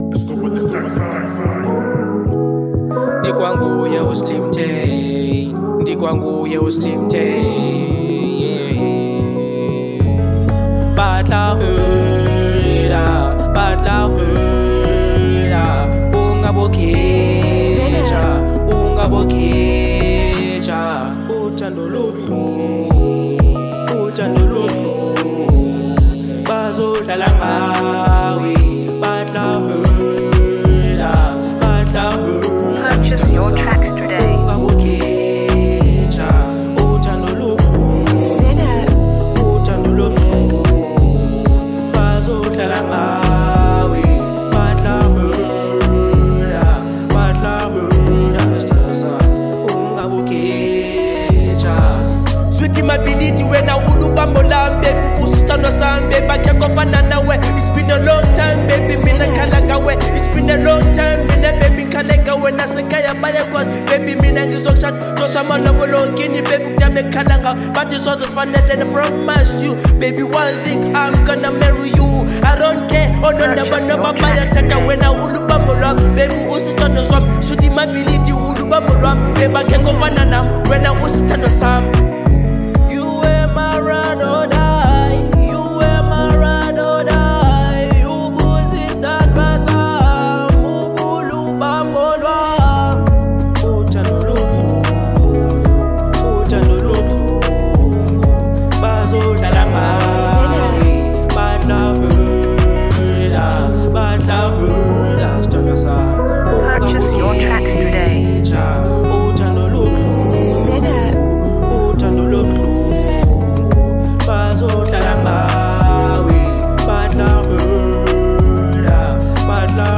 02:16 Genre : Afro Pop Size